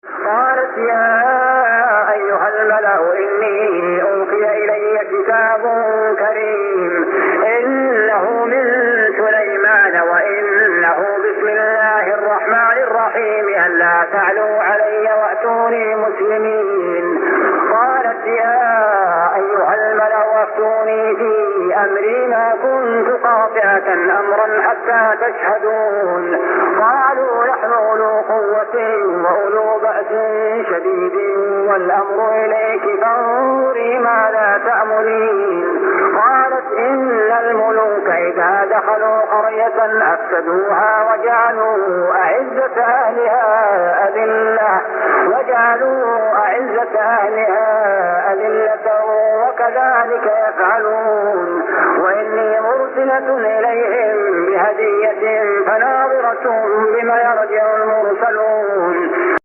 جزء من صلاة التراويح عام 1400هـ سورة النمل 29-35 | Part of Tarawih prayer Surah An-Naml > تراويح الحرم المكي عام 1400 🕋 > التراويح - تلاوات الحرمين